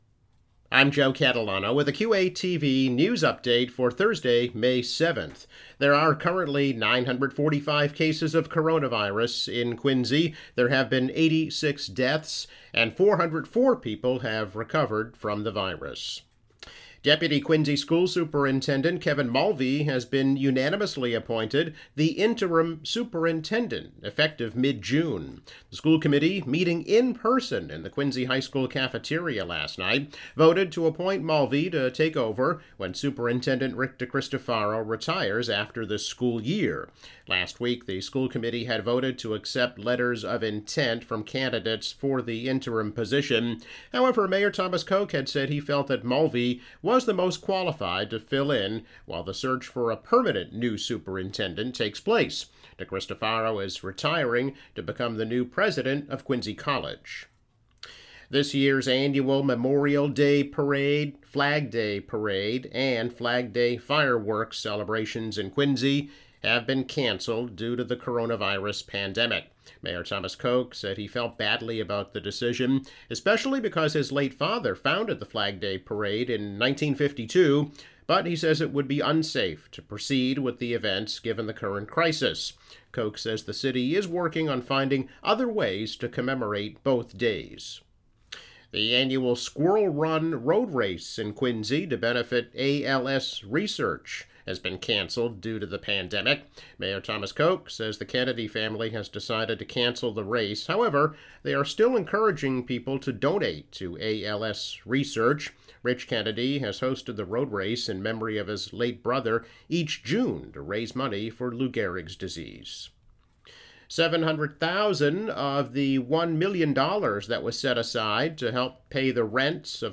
Daily news update.